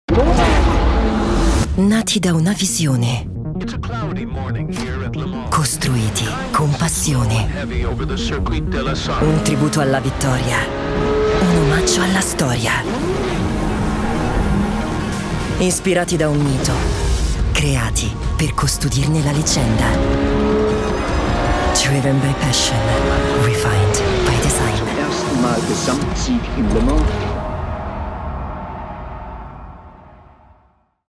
Commercial, Cool, Versatile, Friendly, Warm
Corporate